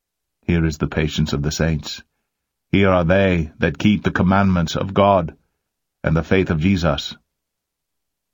Here is what Diella looks like and sounds like....
Diella was created through OpenAI and Microsoft.